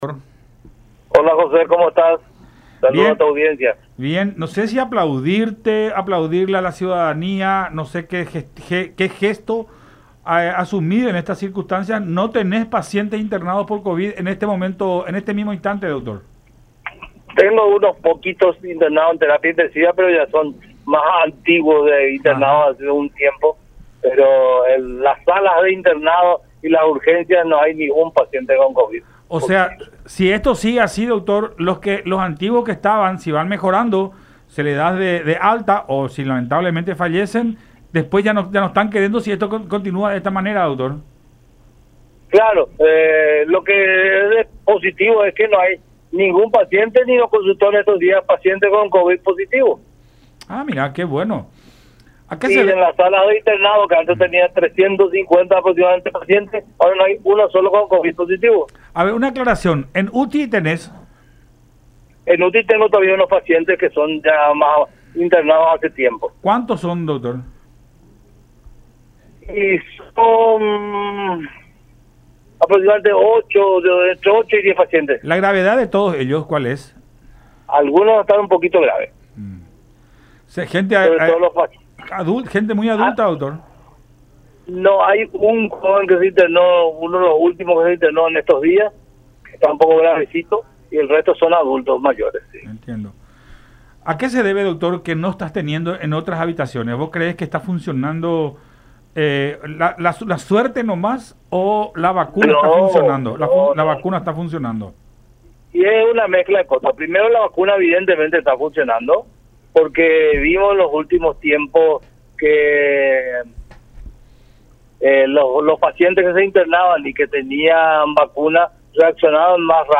en diálogo con Buenas Tardes La Unión.